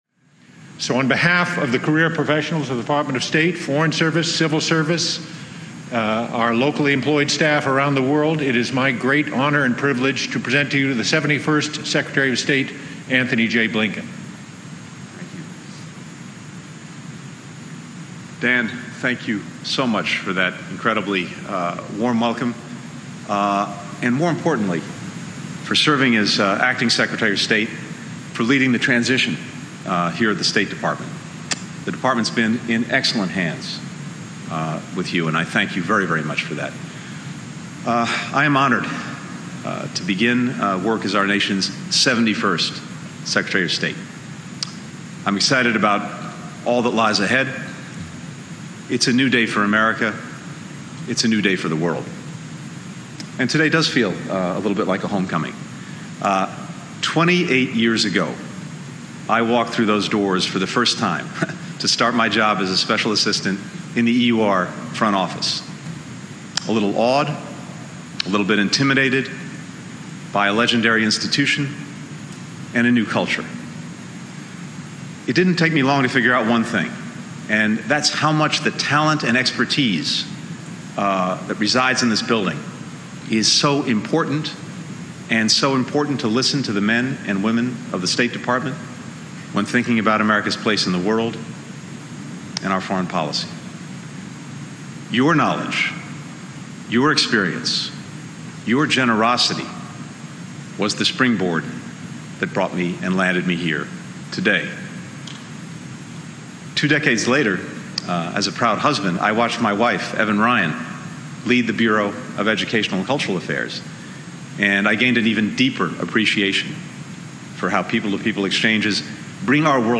First Address to Staff as U.S. Secretary of State
delivered 27 January 2021, C Street Lobby Harry S Truman Building, Washington, D.C.